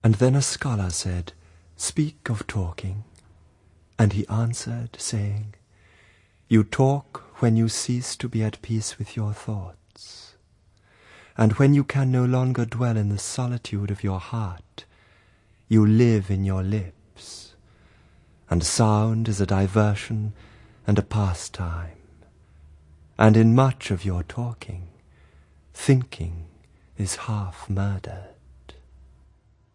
Reading 1: